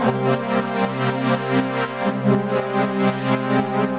Index of /musicradar/sidechained-samples/120bpm
GnS_Pad-alesis1:8_120-C.wav